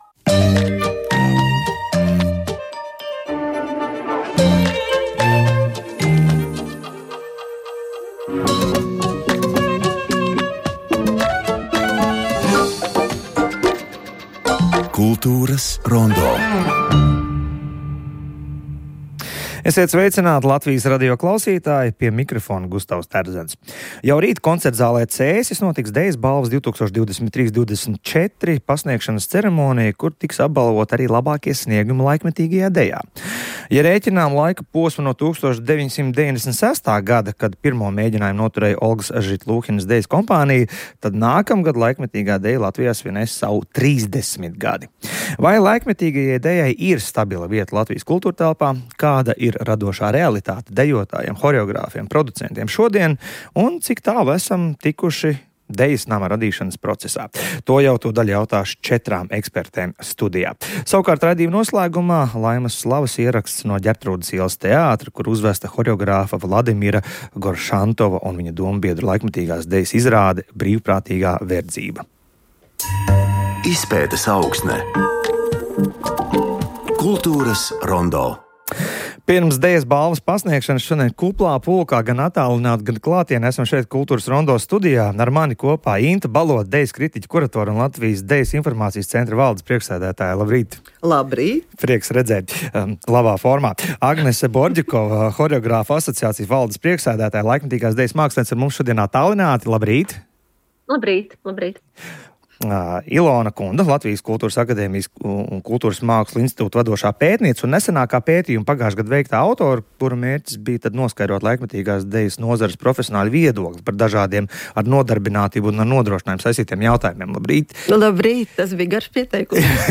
Ierakstos klausāmies diskusijas fragmentus.